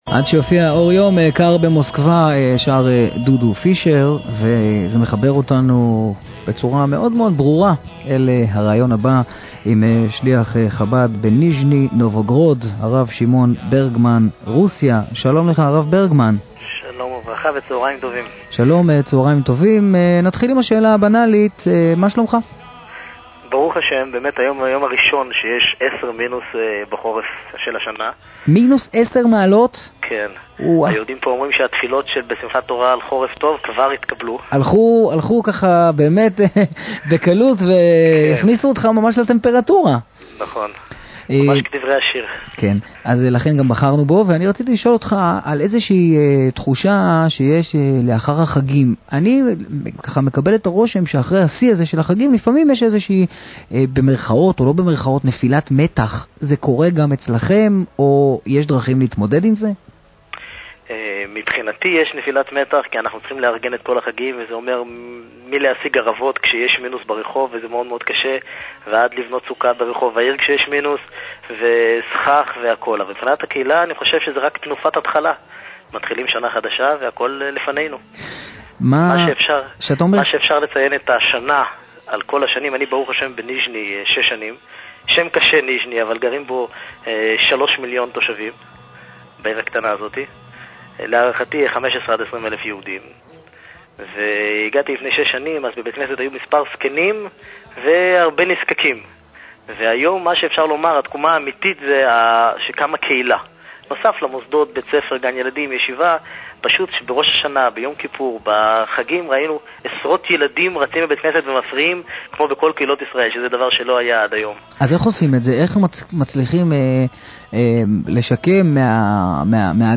ברדיו "קול חי".